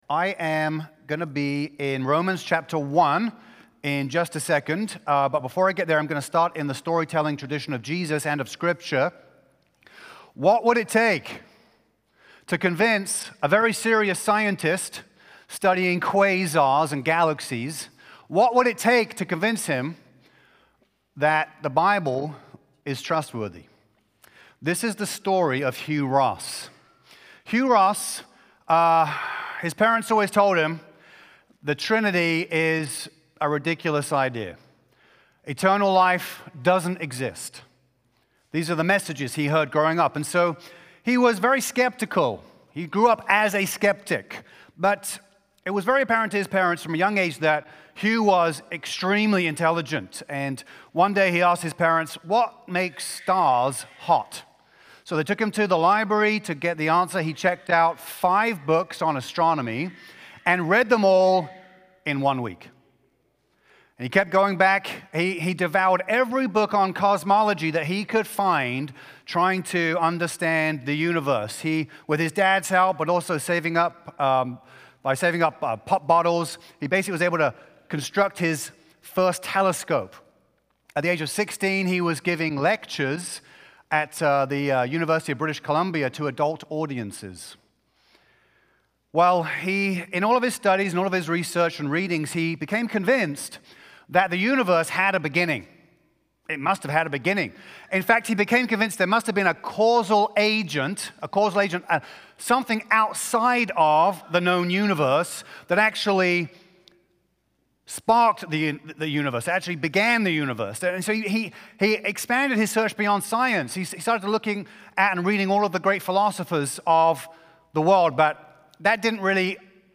A message from the series "Bible Revival."